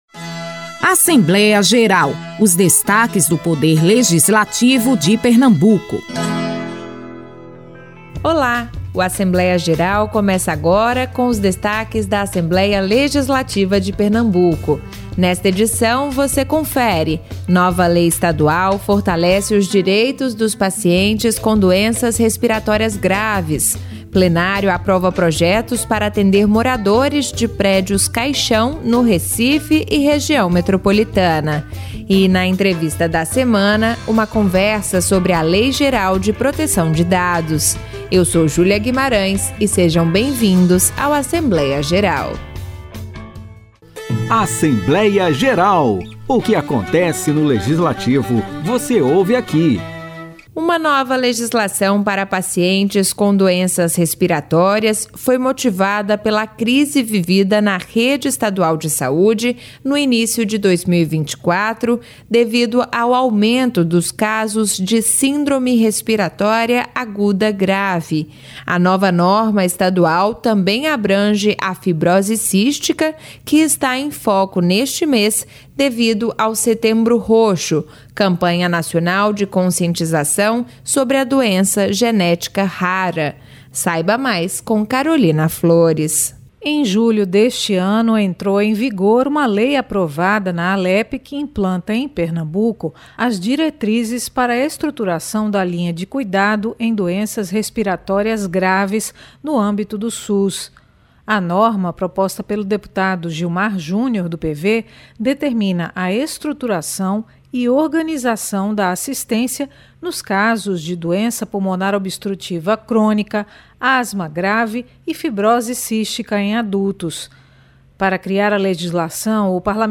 Também nesta edição, uma reportagem fala sobre a aprovação, pelo plenário, do auxílio-moradia para famílias que vivem em prédios-caixão e o aumento no valor do benefício.